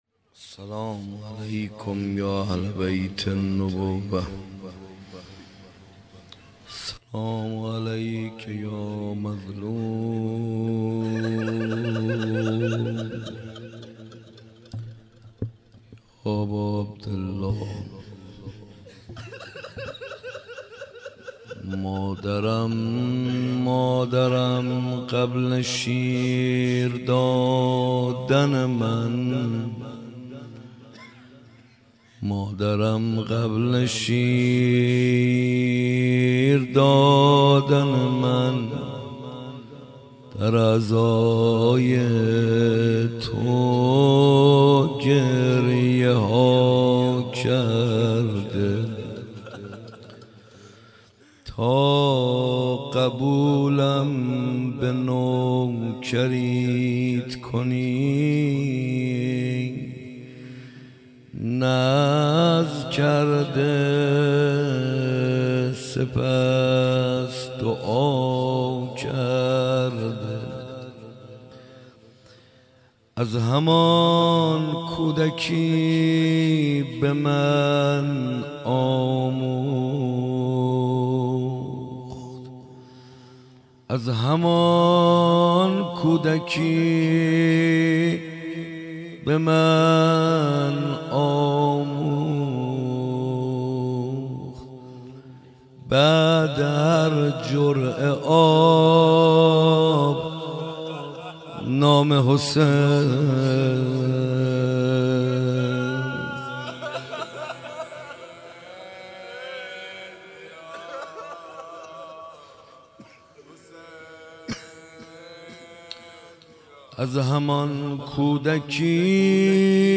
روضه قتلگاه - ظهر عاشورا 1398